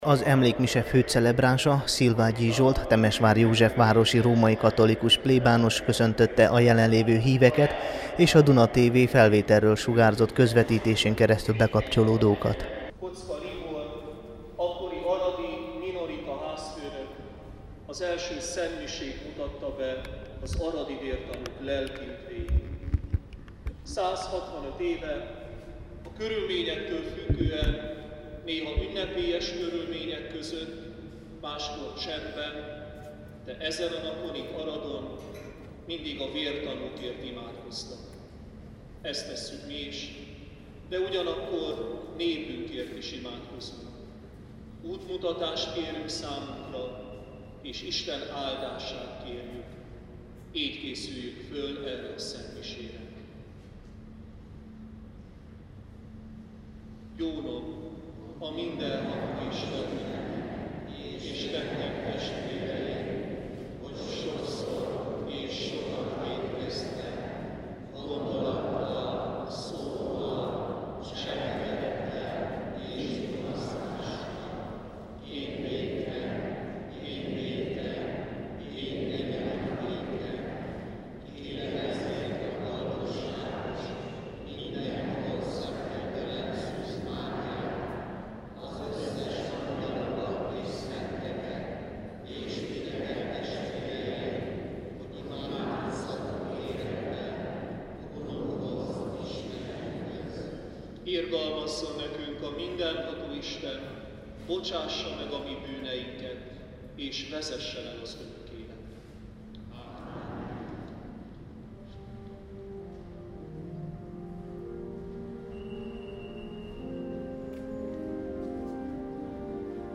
Délelőtt 11 órakor az arad-belvárosi római katolikus minorita templomban megkezdődött az emlékmise az aradi 13 vértanú tiszteletére.
2014_oktober_6_szentmise.mp3